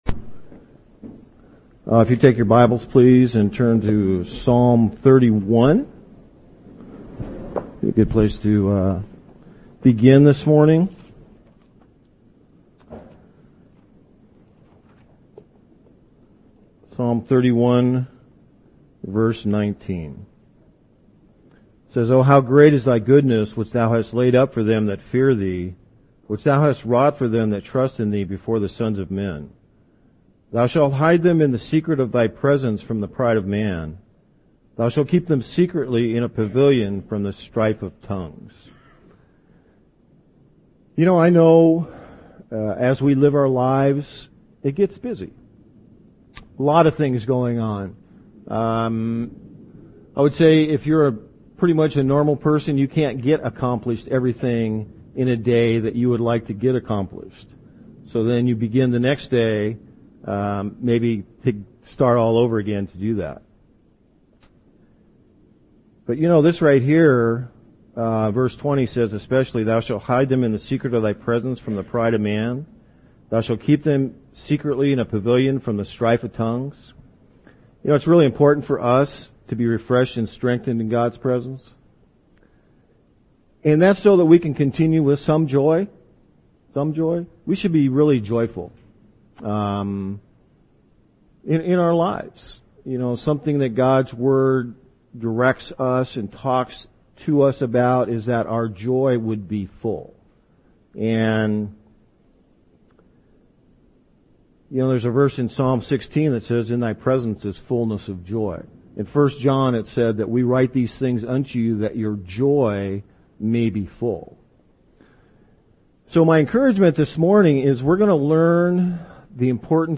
This sharing is an edited version of a teaching done in October of 2009 at Pt Loma Assembly in San Diego, California.